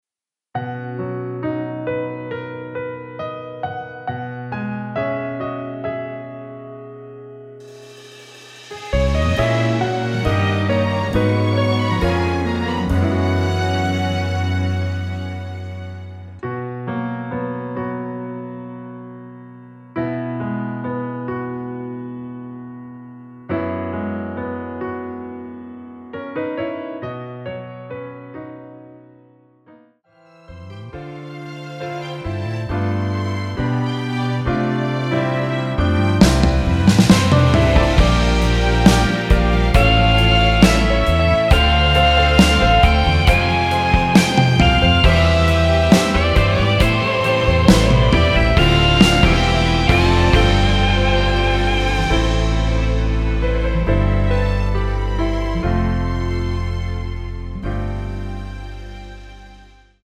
앨범 | O.S.T
앞부분30초, 뒷부분30초씩 편집해서 올려 드리고 있습니다.
중간에 음이 끈어지고 다시 나오는 이유는
위처럼 미리듣기를 만들어서 그렇습니다.